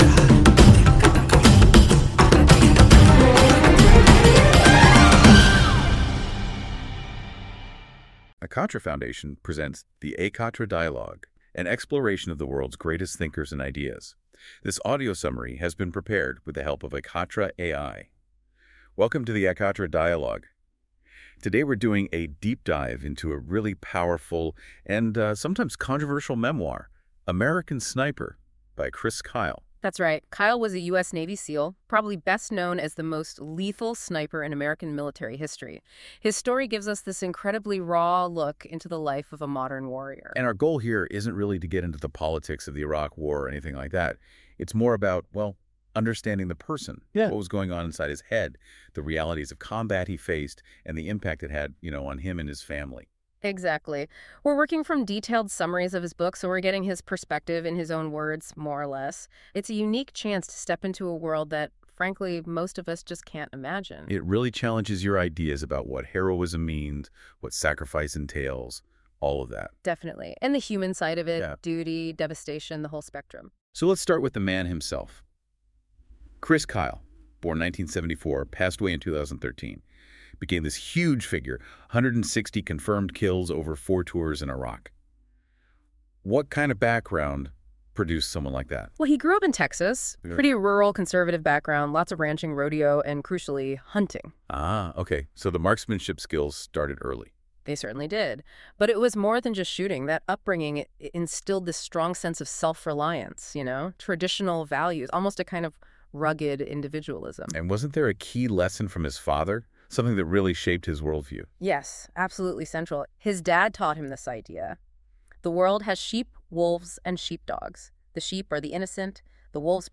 Ekatra audio summary – English